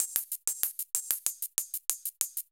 Index of /musicradar/ultimate-hihat-samples/95bpm
UHH_ElectroHatA_95-03.wav